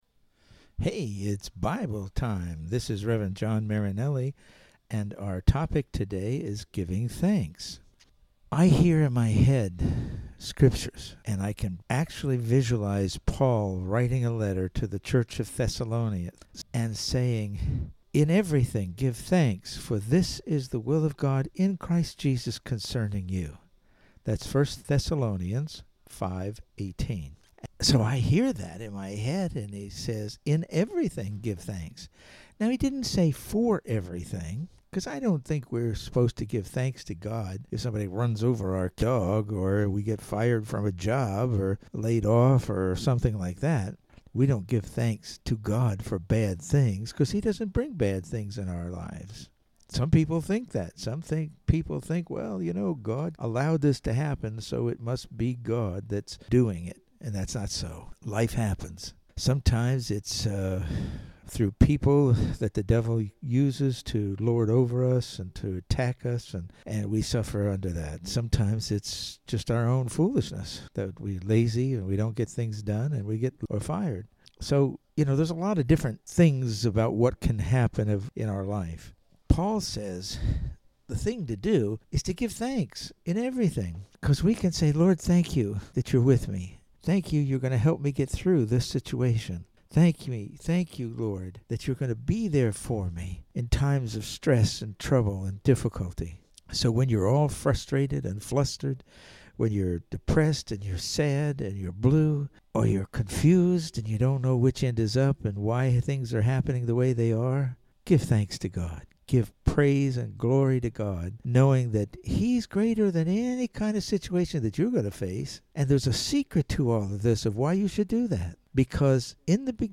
Welcome to Bible Time. The audio files are all 3-6 minute devotional messages that are meant to encourage and